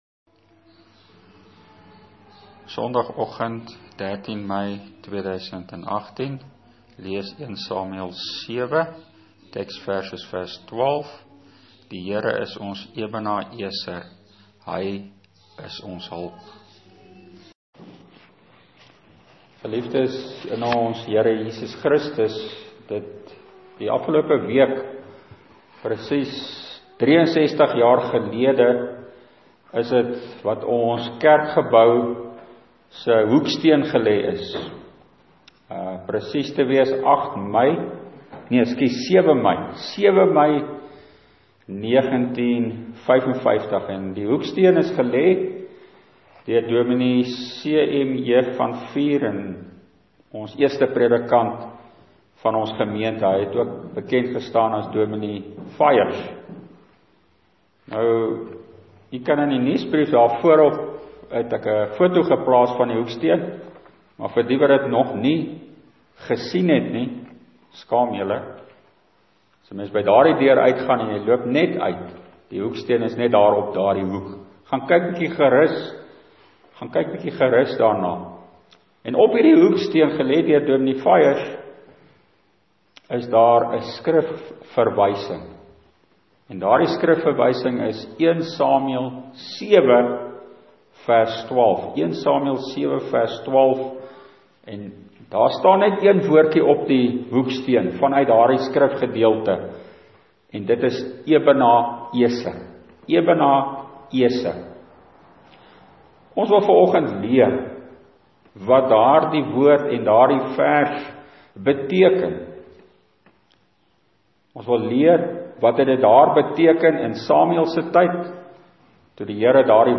Preek: 1 Samuel 7:12